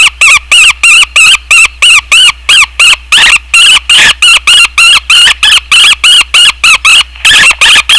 Woodpecker. . one of my favorities
wodpeckr.wav